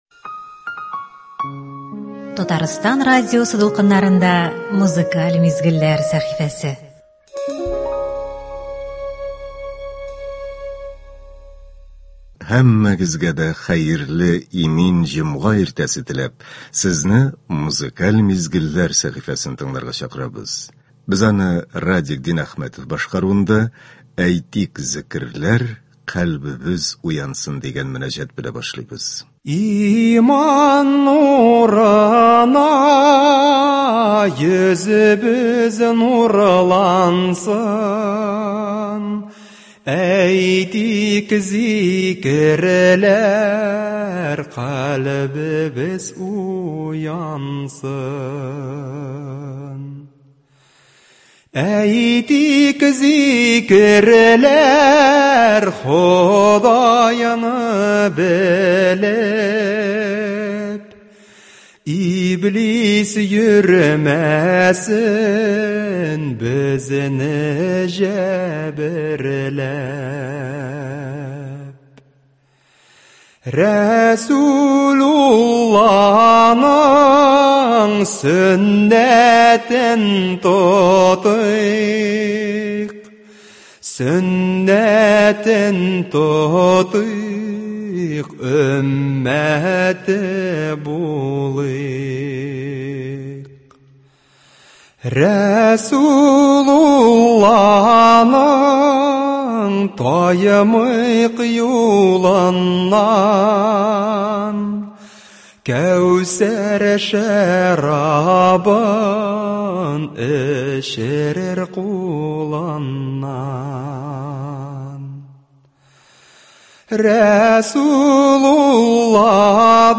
Музыкаль мизгелләр – һәр эш көнендә иртән безнең эфирда республикабыз композиторларының иң яхшы әсәрләре, халкыбызның яраткан җырлары яңгырый.